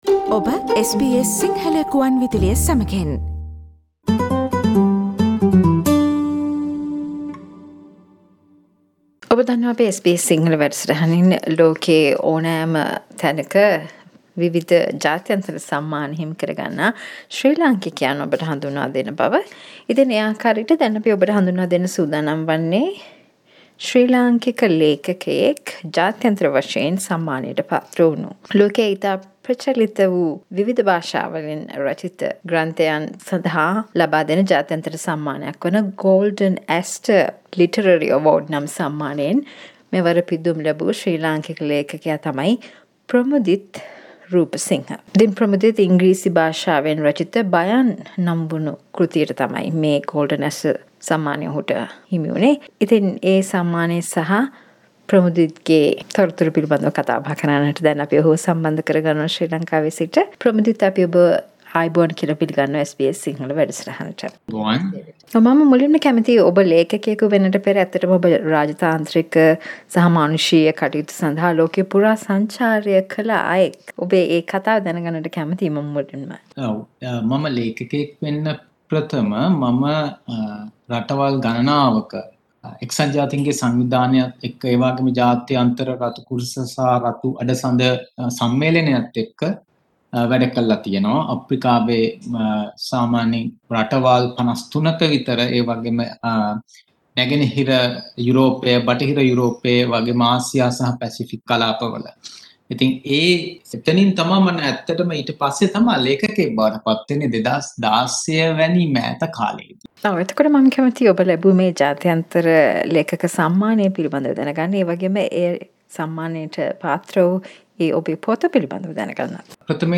SBS සිංහල සිදු කල පිළිසදරට සවන් දෙන්න .